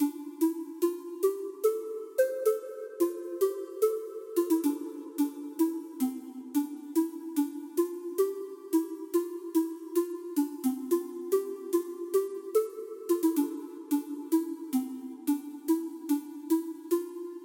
Tag: 110 bpm House Loops Synth Loops 2.94 MB wav Key : Unknown